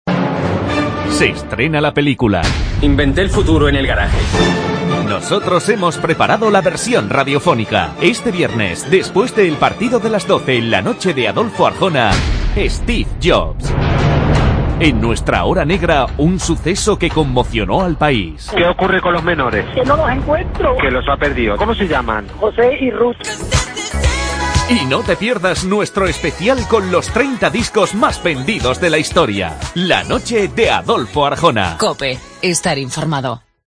Promoción la noche de Cope.